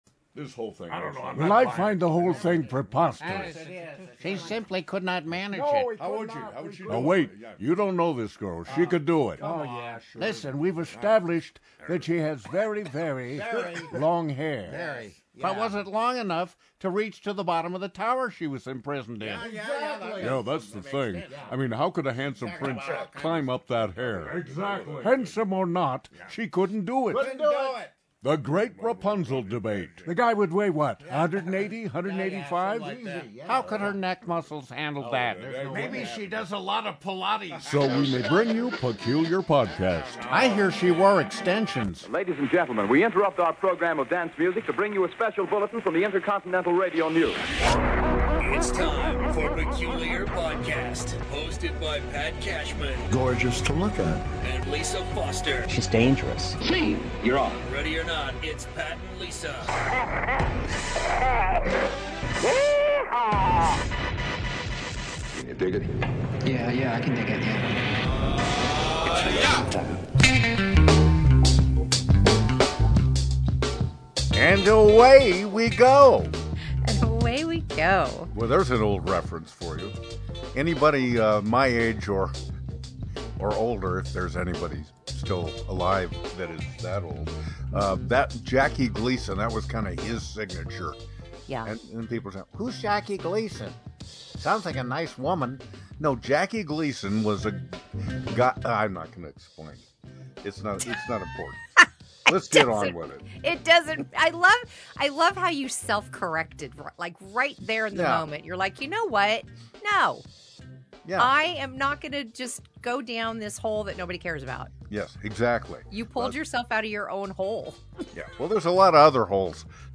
Various Japanese toilet masking SFX (YouTube)